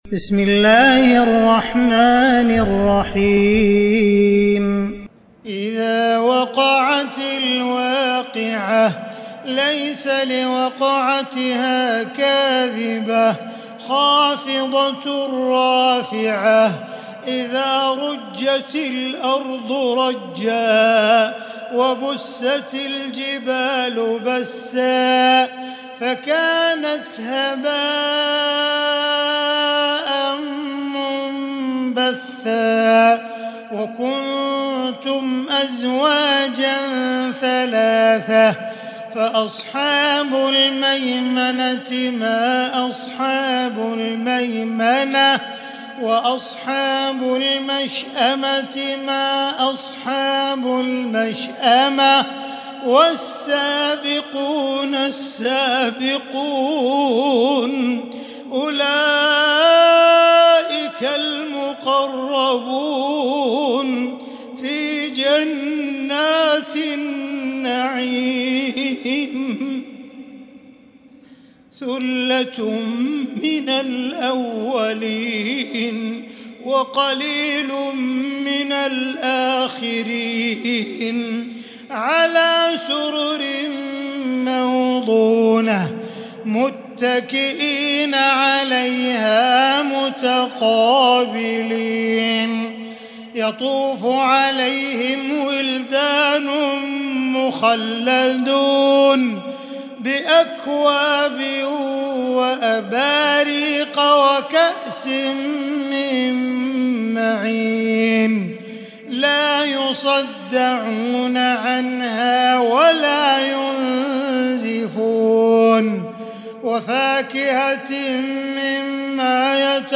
سورة الواقعة كاملة للشيخ عبدالرحمن السديس من عشائيات المسجد الحرام 🕋 > السور المكتملة للشيخ عبدالرحمن السديس من الحرم المكي 🕋 > السور المكتملة 🕋 > المزيد - تلاوات الحرمين